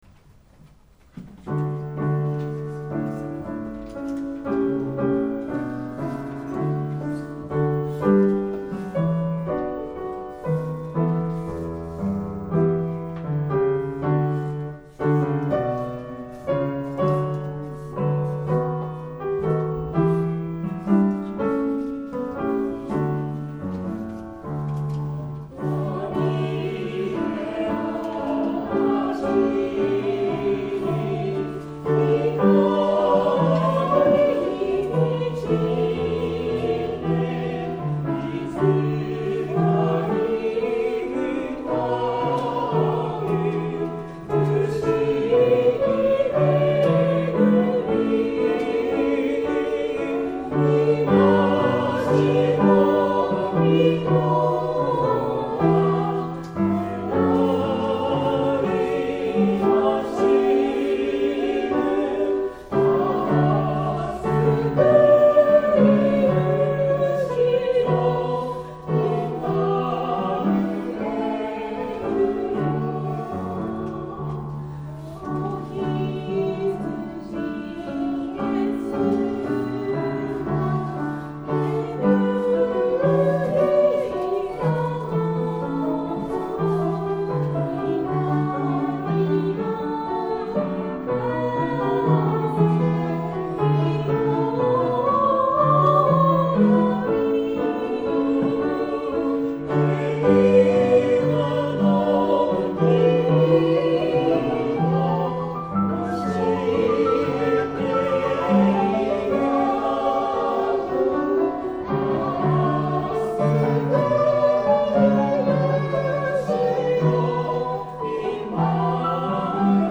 聖歌隊
合唱